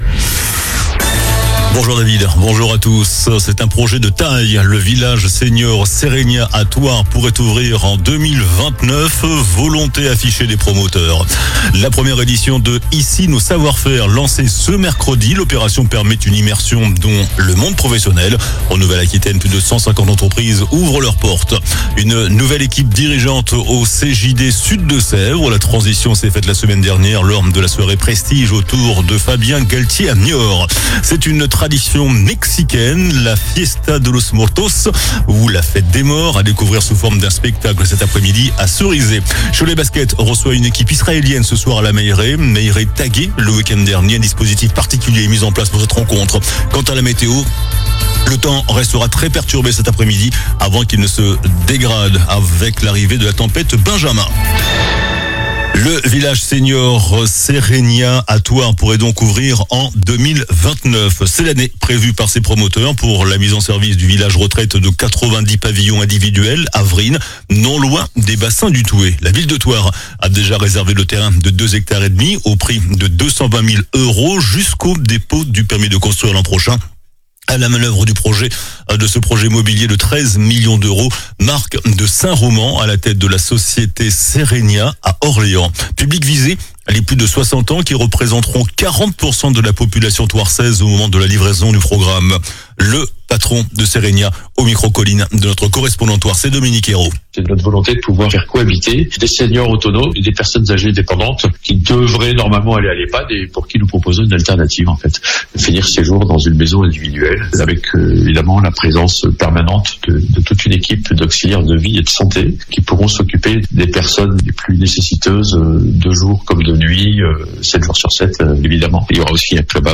JOURNAL DU MERCREDI 22 OCTOBRE ( MIDI )